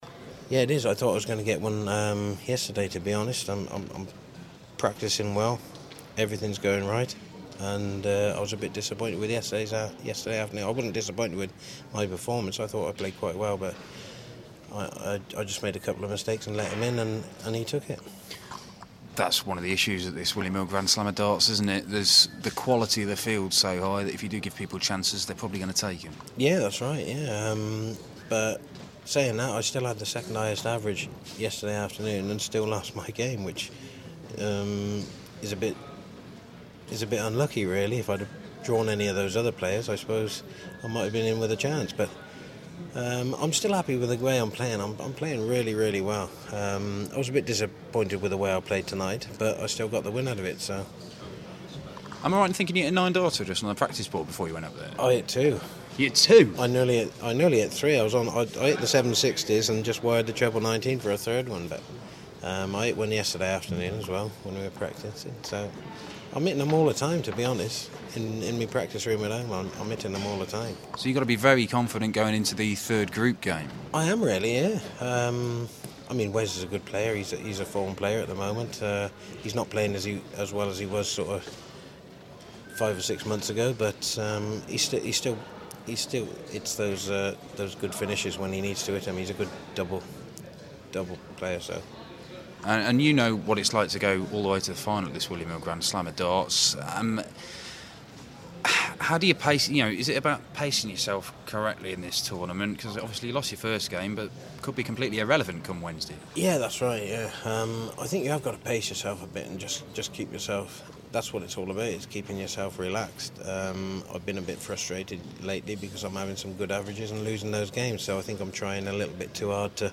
William Hill GSOD - Jenkins Interview (2nd game)